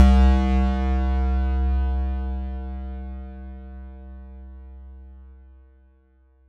Analog Bass 2.wav